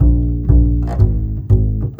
Rock-Pop 11 Bass 05.wav